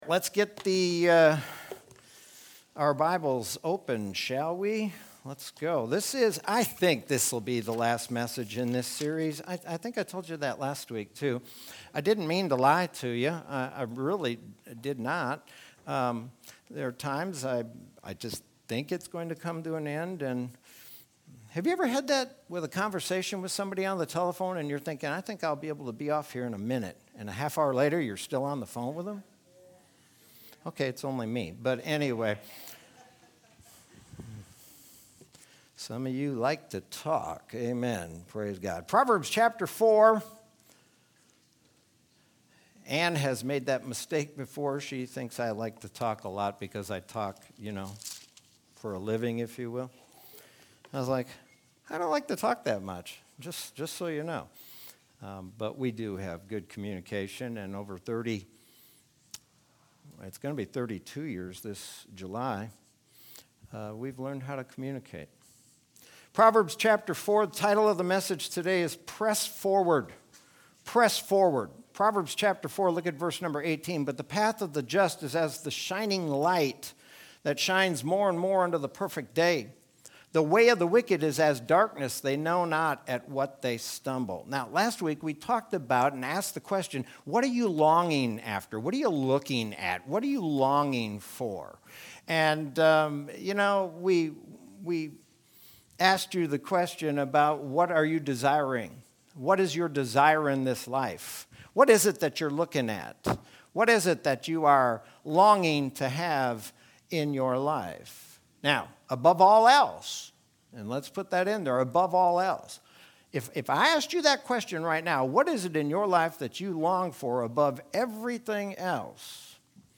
Sermon from Sunday, January 17th, 2021.